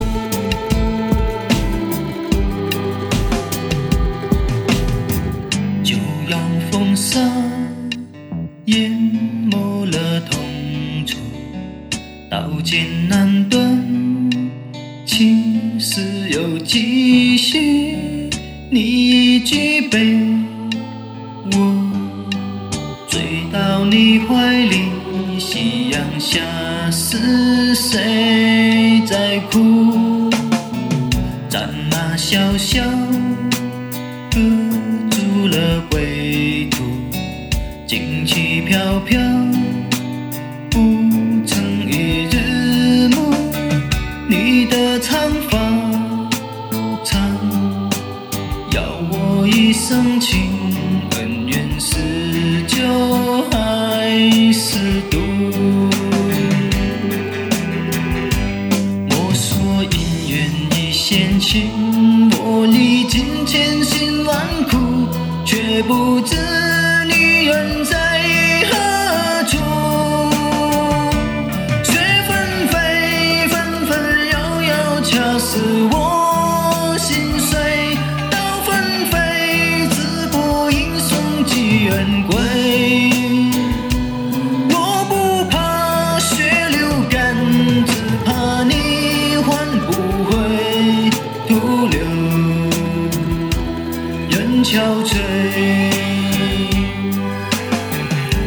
主打歌曲